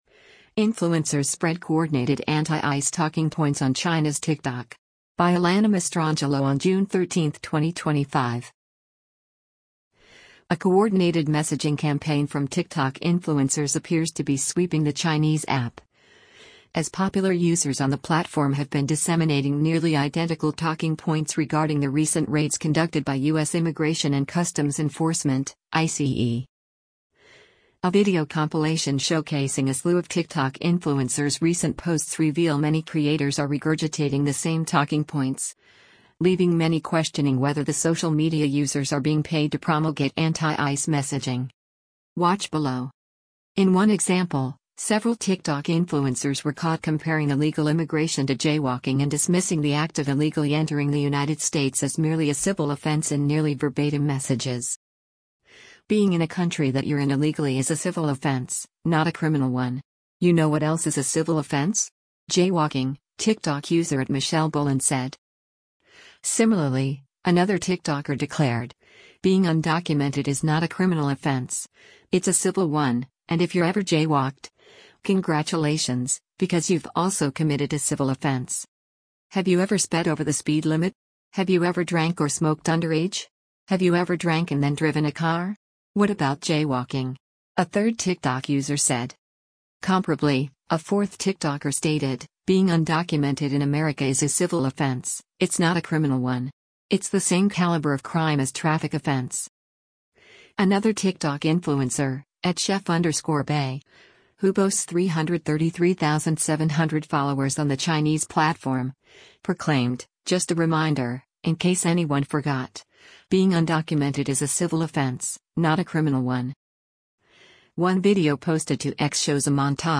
A video compilation showcasing a slew of TikTok influencers’ recent posts reveal many creators are regurgitating the same talking points, leaving many questioning whether the social media users are being paid to promulgate anti-ICE messaging.